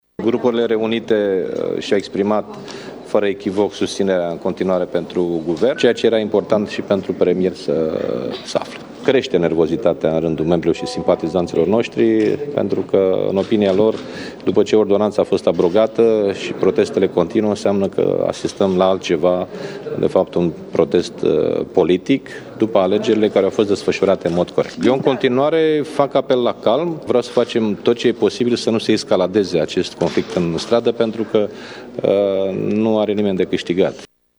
Președintele PSD, Liviu Dragnea, a declarat la finalul ședinței de grup a social-democraților că actualul Cabinet are „totala” sa susținere și a negat existența unor tensiuni între el și șeful Executivului.